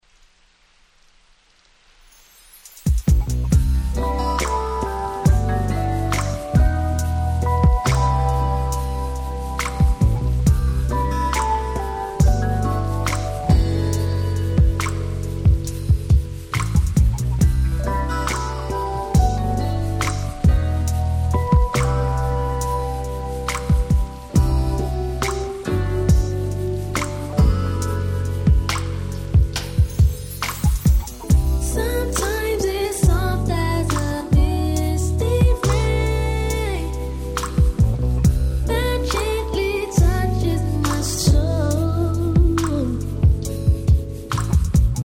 97' Nice Slow Jam !!
タイトル通り雨の良く似合う素晴らしいSlowです。